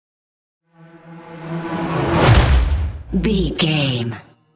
Whoosh to hit electronic
Sound Effects
Atonal
dark
futuristic
intense
tension